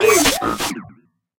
Cri de Gambex dans Pokémon HOME.